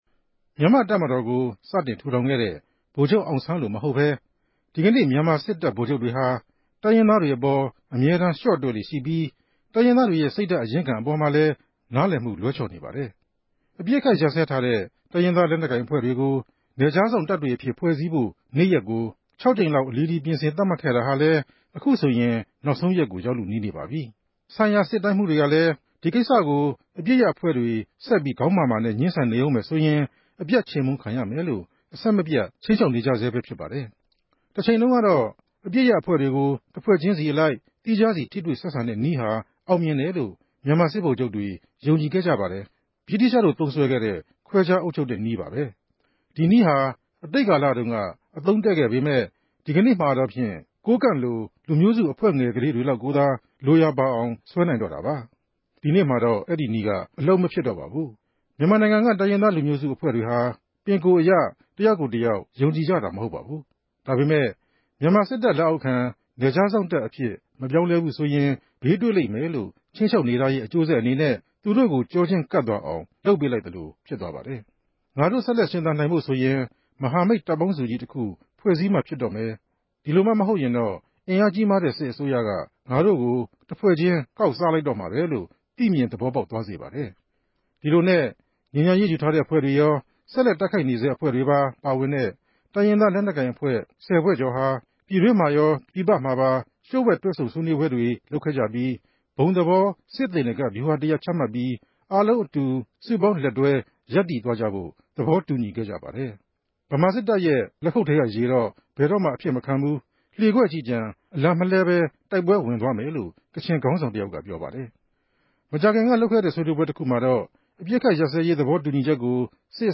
ဖတ်ုကား တင်ူပထားတာ ူဖစ်ပၝတယ်